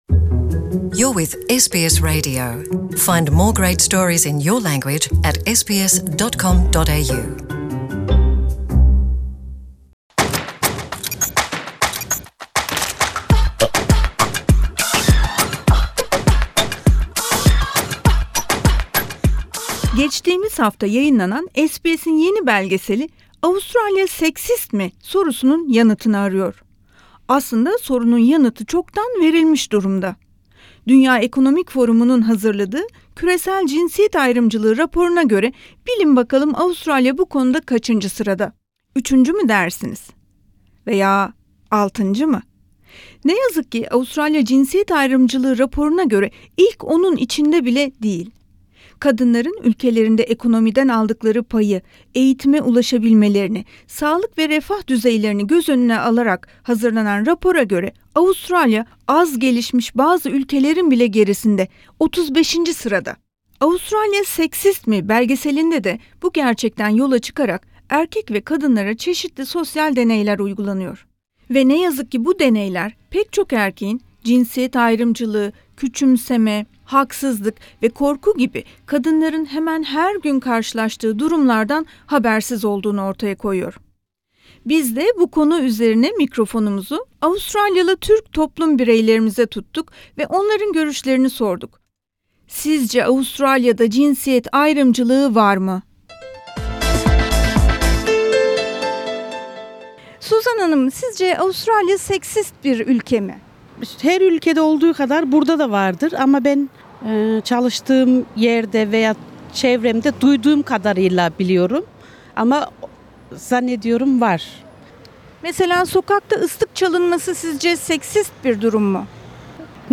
So what do our women think about every day aspects of this topic? We spoke 5 women from Turkish Australian community and asked their experience...